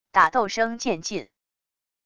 打斗声渐近wav音频